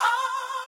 TS Chant 4.wav